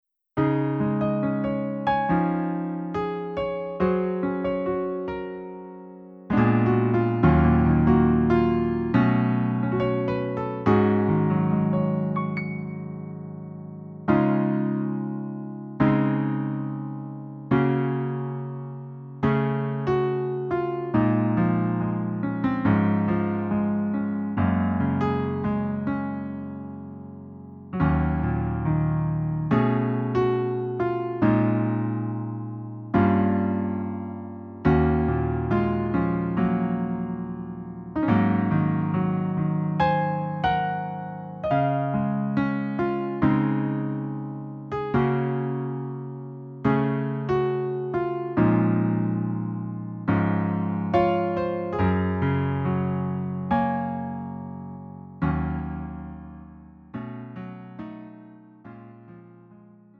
음정 -1키 4:09
장르 가요 구분 Lite MR